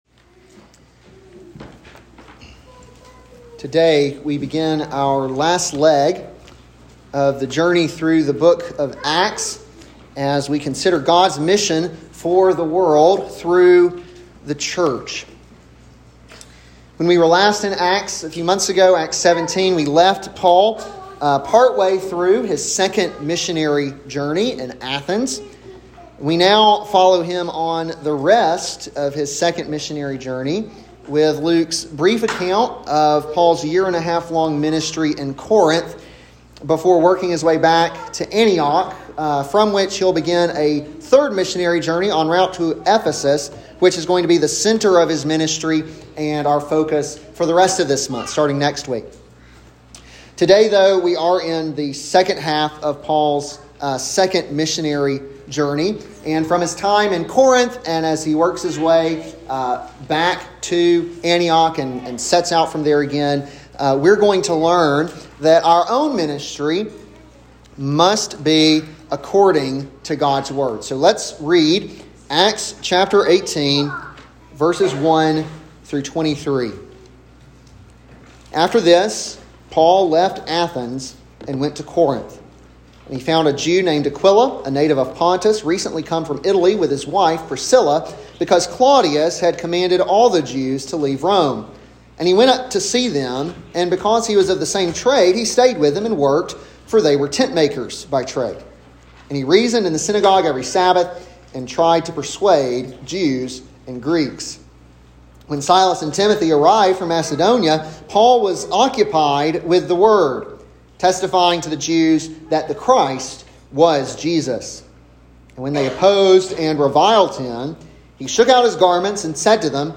an expository sermon on Acts 18:1-23